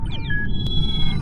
Among Us Joining Lobby Sound Effect Free Download